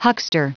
Prononciation du mot huckster en anglais (fichier audio)
Prononciation du mot : huckster
huckster.wav